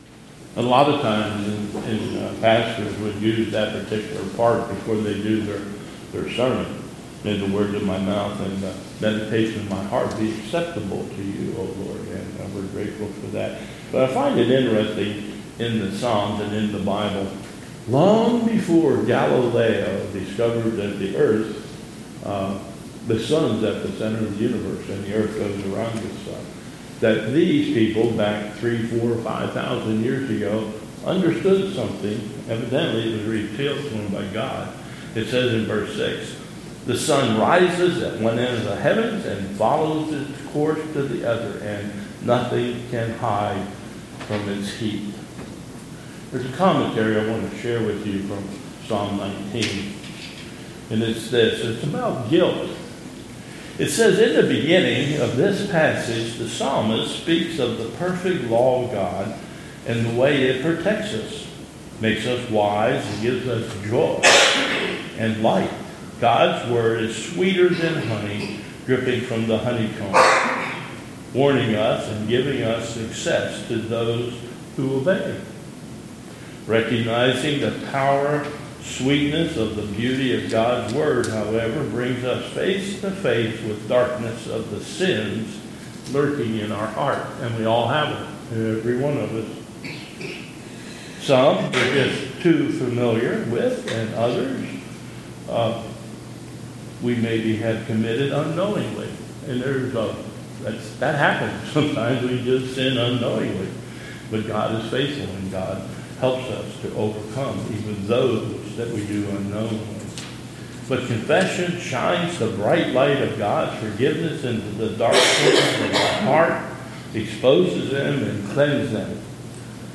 2022 Bethel Covid Time Service
Commentary on the Reading